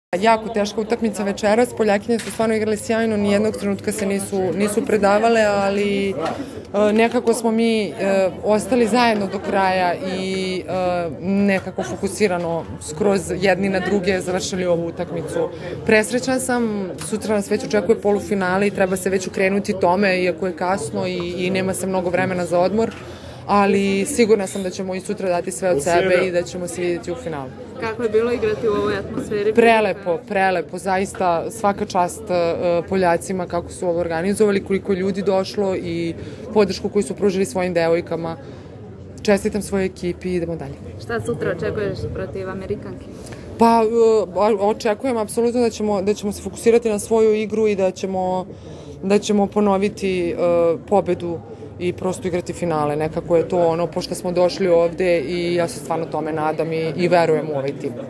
Izjava Bojane Drče